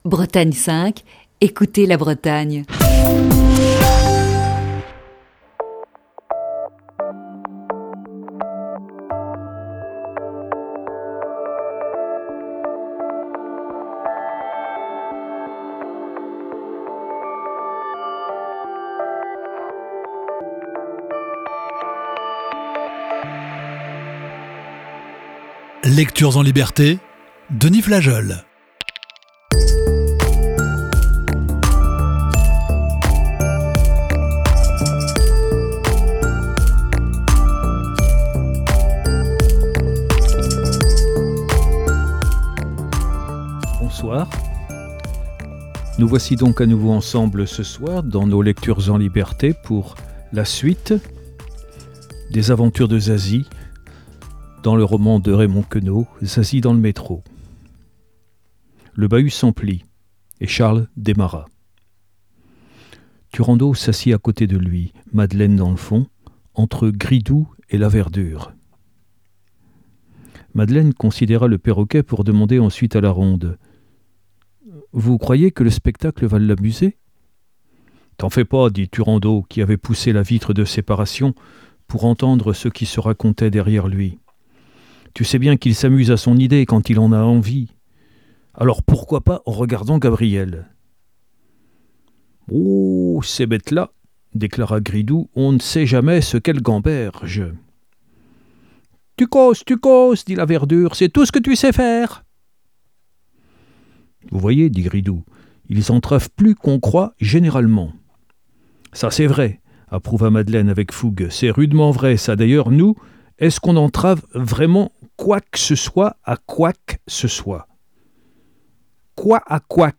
Lecture(s) en liberté